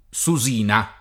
susina [